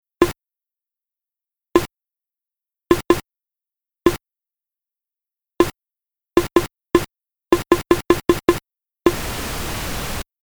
すると、Macの通知音の「ポンッ」という音が流れました。
最初のサウンドのように「ポンッ」の音が一度だけではなくランダムに鳴り、しかもノイズが乗っており、最後はノイズのみが鳴っているのです。